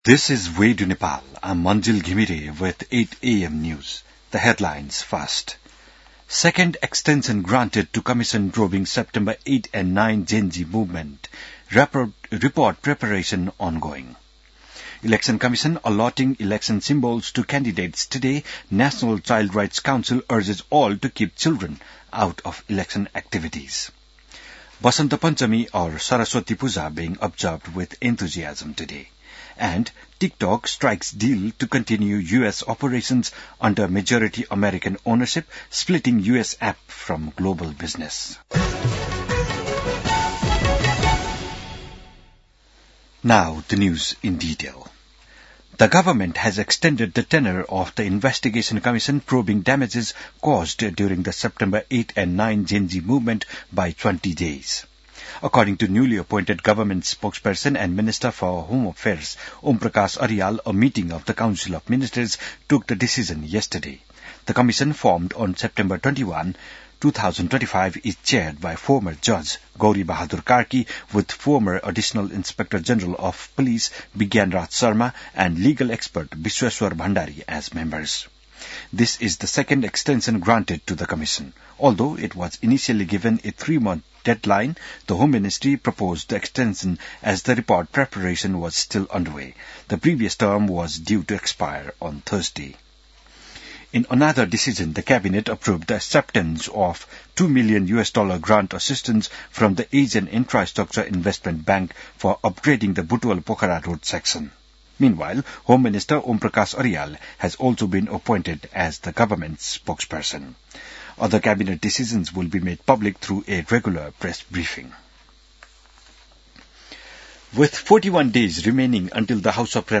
बिहान ८ बजेको अङ्ग्रेजी समाचार : ९ माघ , २०८२